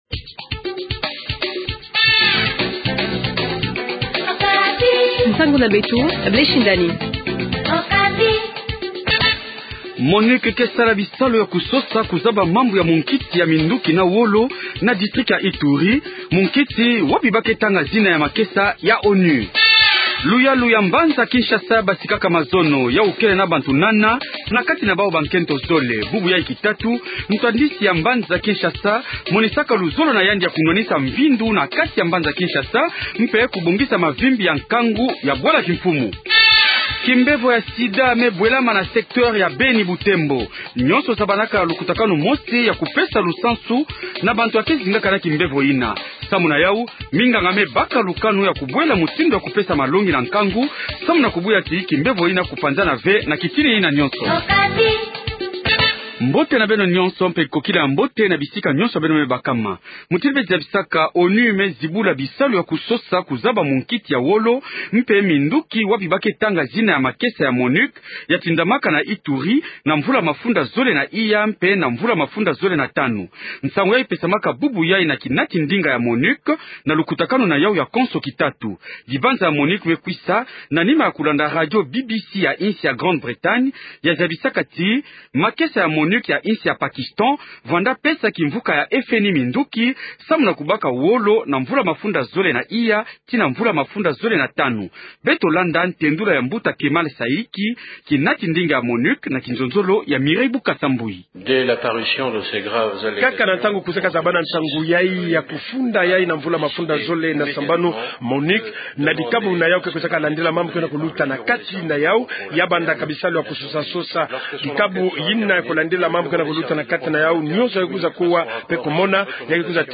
Journal Kikongo Soir
André Kimbuta, vous allez l’entendre, s’en défend, il y avait des urgences qu’il fallait intervenir. Titre 3 : Des cas de personne atteinte du VIH Sida augmente dans le secteur Butembo-Beni.